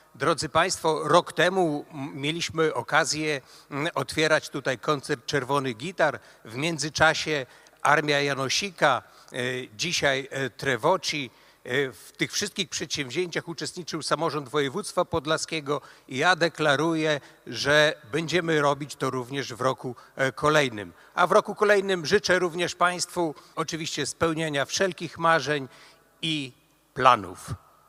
Członek zarządu województwa podlaskiego Jacek Piorunek zwrócił uwagę na fakt, że to kolejne takie wydarzenie w Katedrze.